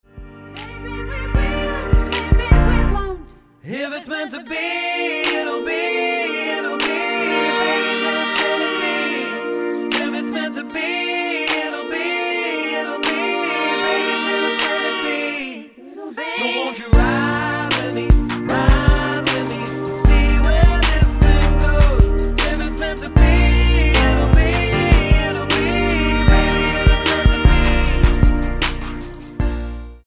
Tonart:Bb mit Chor
Die besten Playbacks Instrumentals und Karaoke Versionen .